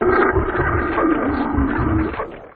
c_gettin_hit2.wav